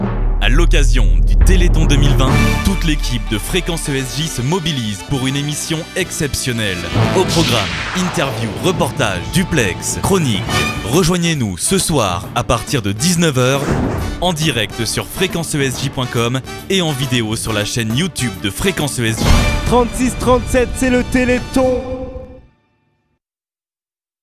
medleys (mini-mix)
• Réalisés par nos DJ producteurs